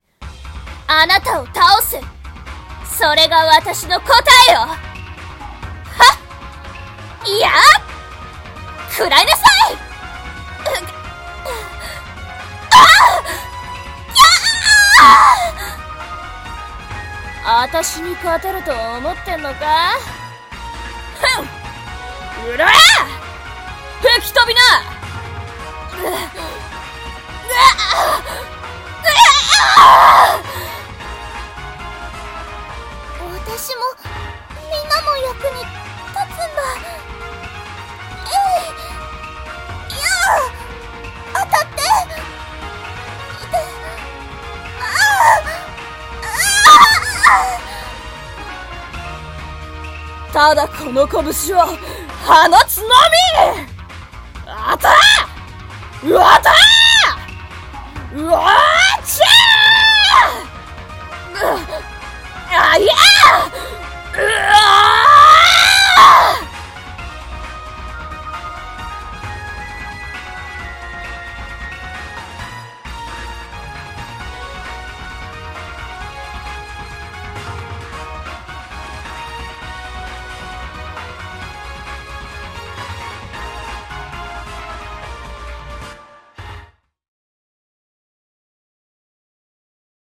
【声劇 演じ分け】ゲームのキャラボイス選択画面